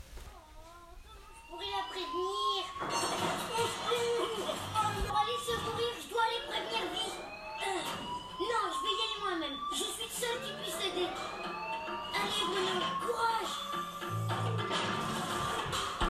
Je suis à la recherche d'une sirène ayant le même son que cet enregistrement (désolé pour la qualité).
audio-sirene.mp3